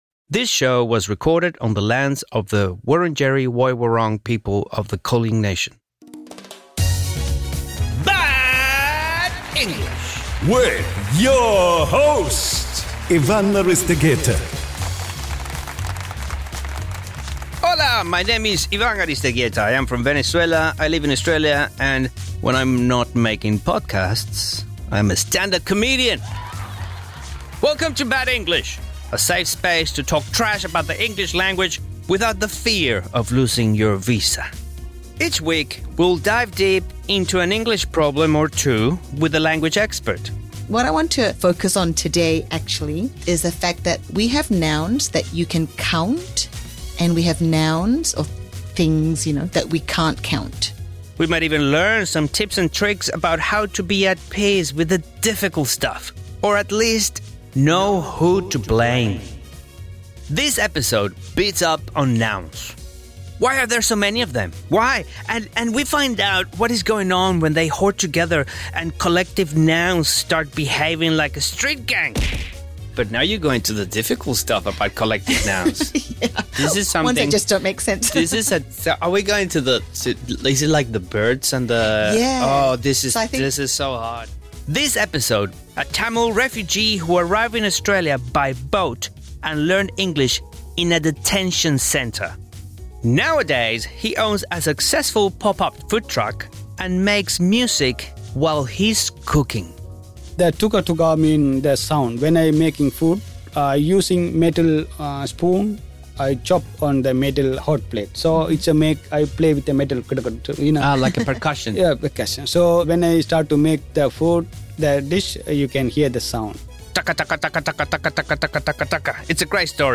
Bad English is a comedy podcast from SBS Audio about the lows of learning the world's most widely spoken and studied language.